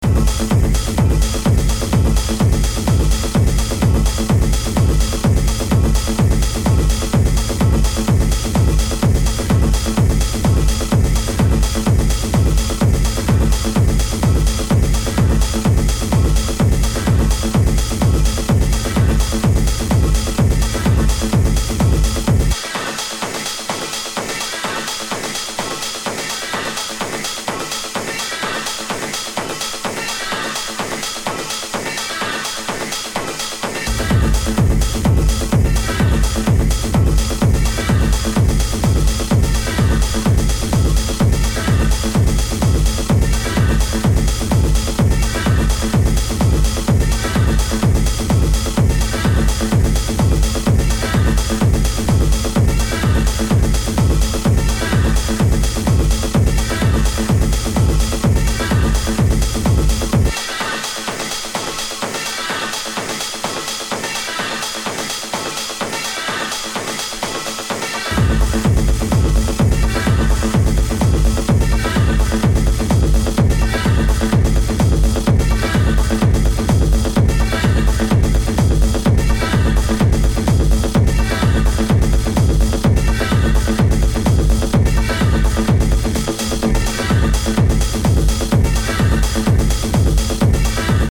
Three hard hitting techno tracks.